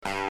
nt snyth 2.wav